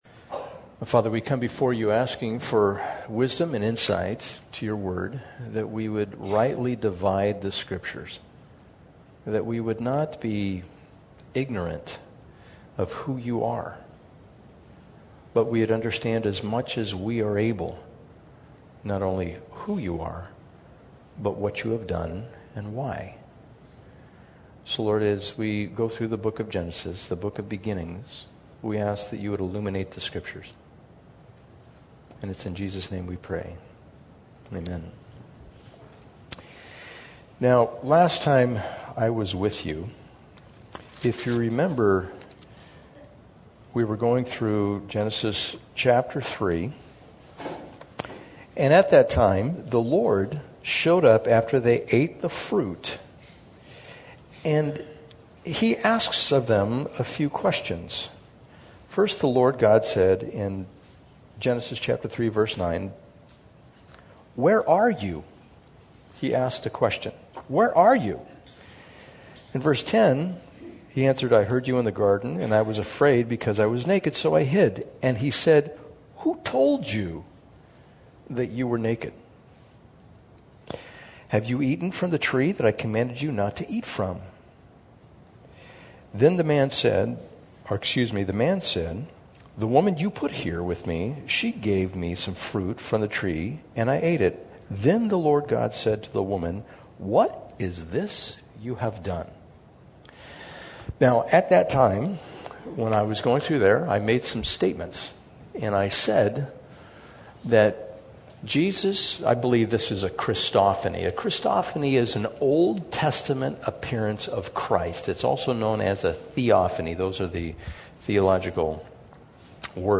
Passage: Genesis 3 Service Type: Sunday Morning